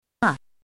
怎么读